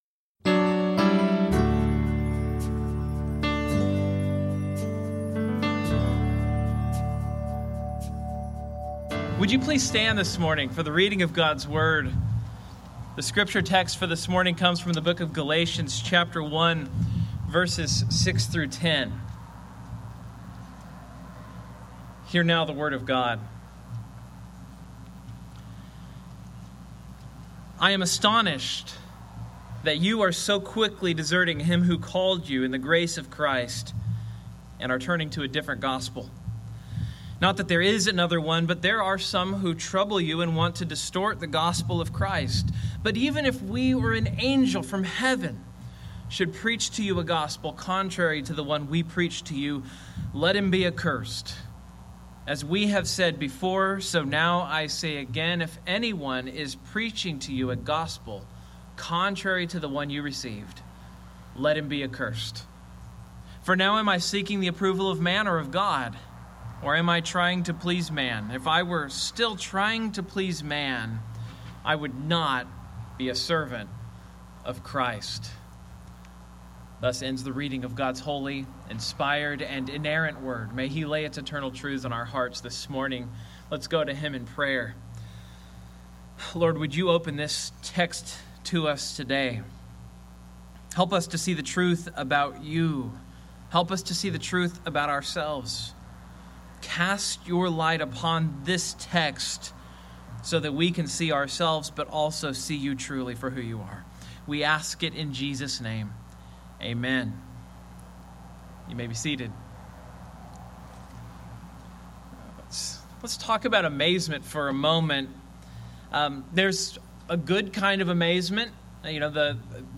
Due to a power-outage during the service, the video from the zoom presentation for this sermon was corrupted. The audio was unaffected and is posted here.